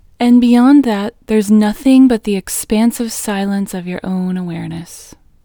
WHOLENESS English Female 15